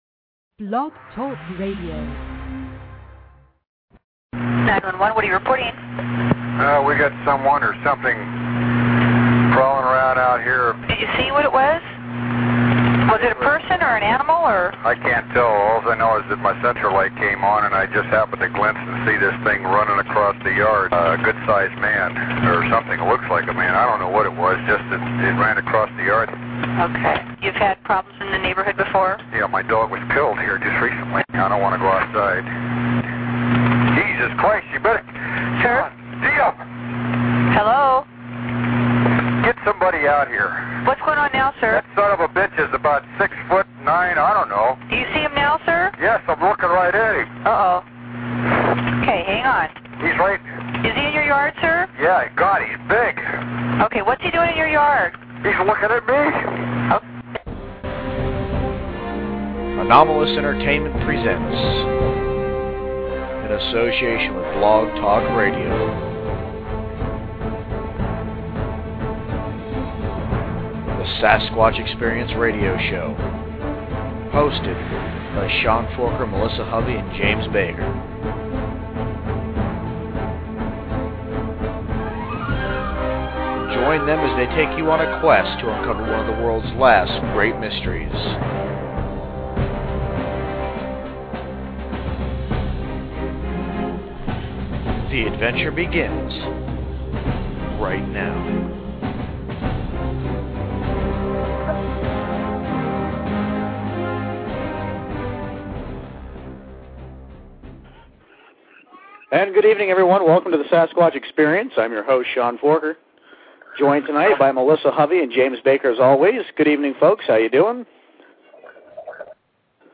(Radio Show) The Sasquatch Experience Retrieved from " http